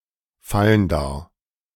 Vallendar (German pronunciation: [ˈfaləndaʁ]
De-Vallendar.ogg.mp3